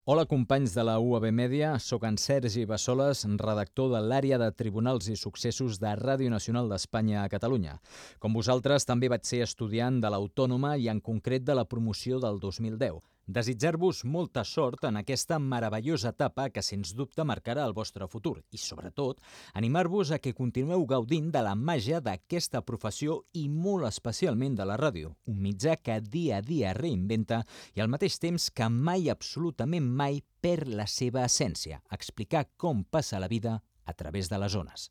Salutació amb motiu de l'inici de la temporada radiofònica d'UAB Ràdio 2023-2024
FM